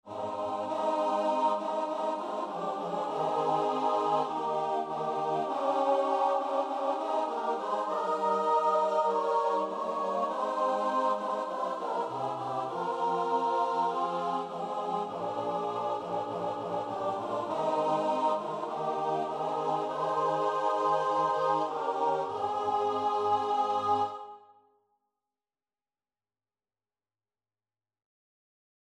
Free Sheet music for Choir (SATB)
SopranoAltoTenorBass
4/4 (View more 4/4 Music)
Classical (View more Classical Choir Music)